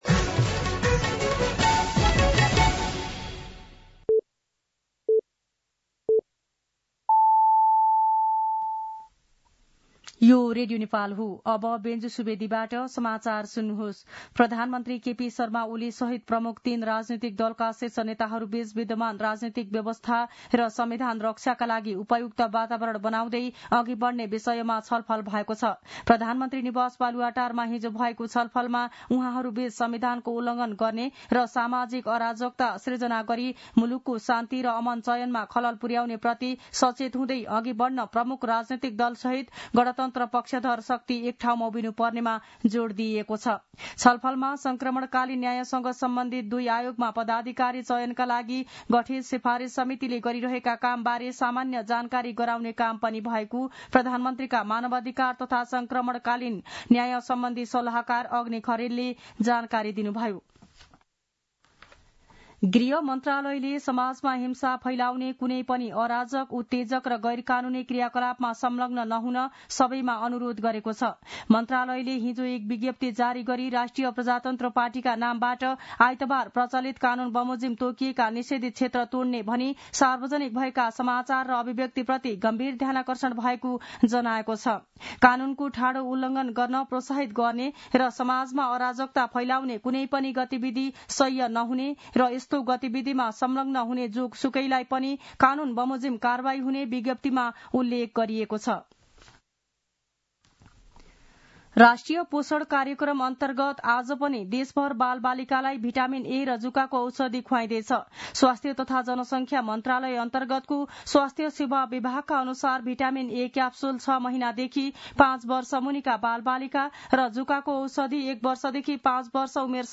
An online outlet of Nepal's national radio broadcaster
मध्यान्ह १२ बजेको नेपाली समाचार : ७ वैशाख , २०८२